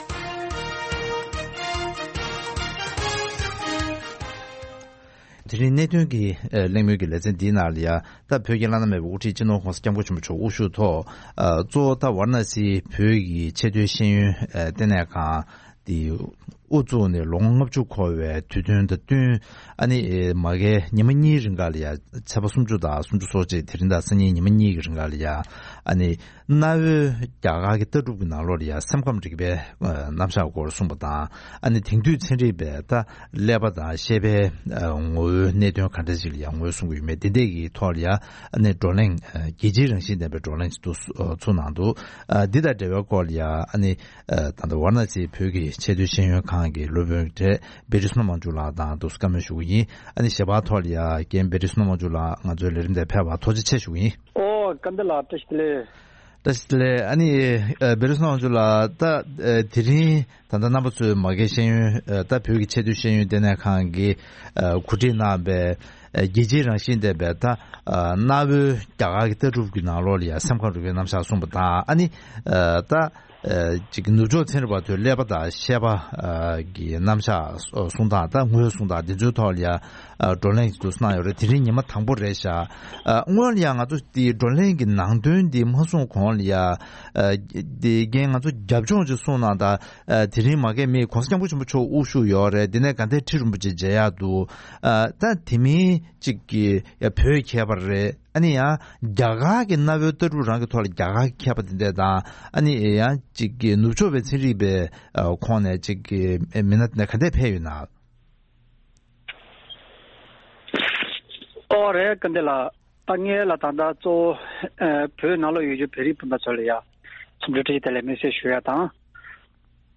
རྒྱ་གར་གྱི་ལྟ་གྲུབ་ནང་གི་སེམས་ཁམས་རིག་པའི་གནད་དོན་དང་དེང་དུས་ཚན་རིག་དབར་གྱི་བགྲོ་གླེང་སྐོར་གླེང་མོལ།